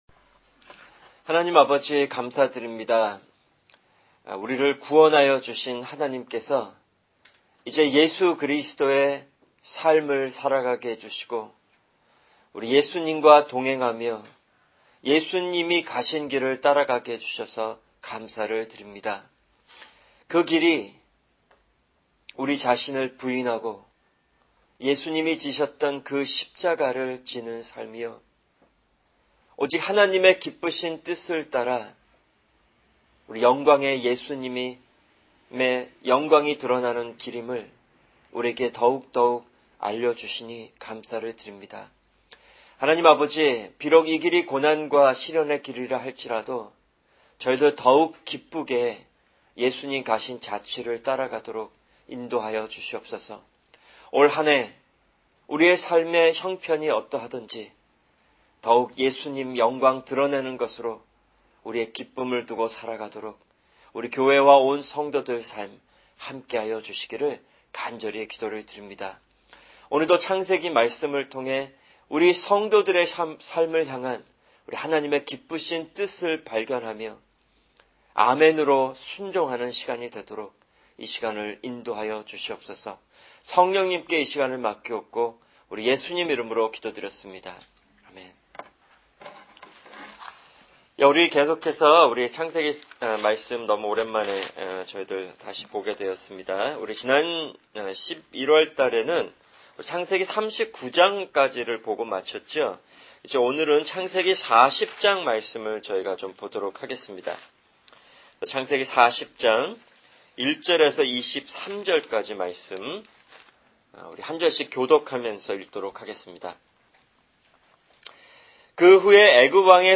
[성경공부] 창세기(102) 40:1-23